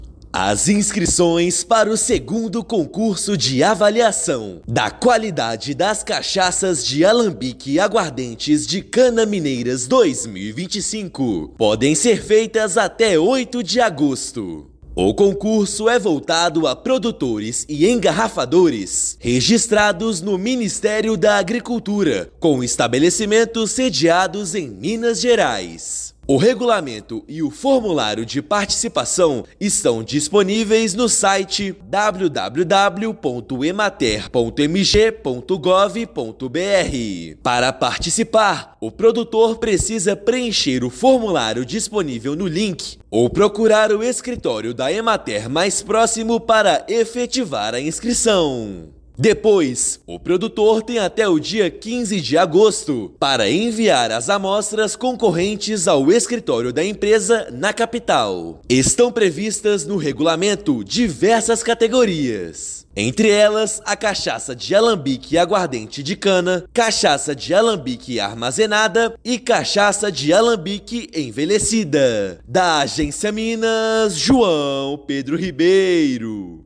Produtores de cachaça e aguardente de cana ganham mais tempo para participar da premiação organizada pela Emater-MG. Ouça matéria de rádio.